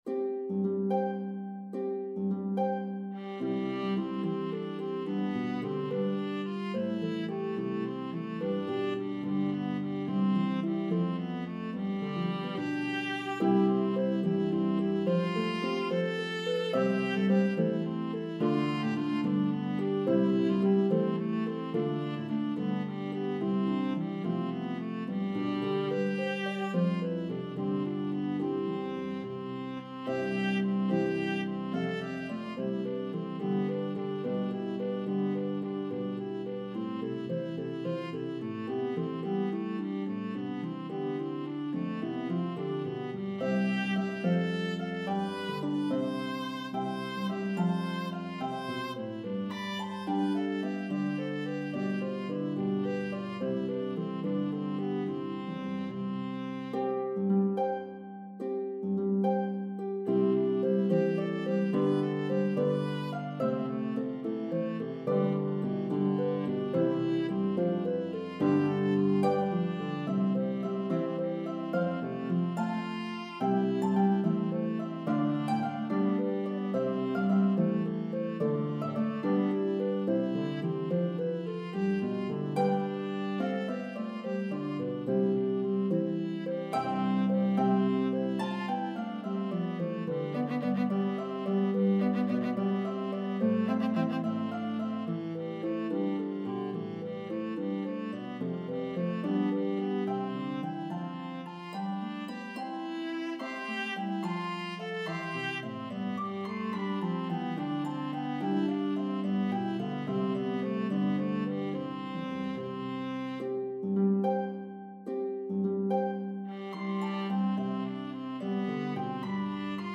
The Harp part is playable on either Lever or Pedal Harps.